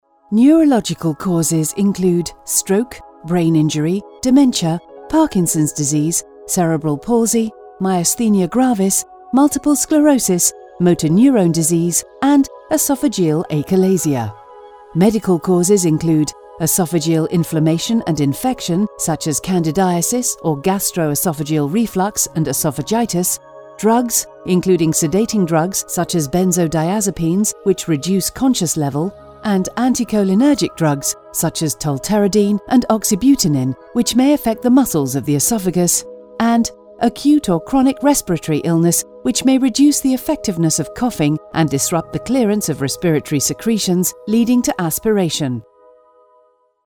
UK British Female Voiceover
MEDICAL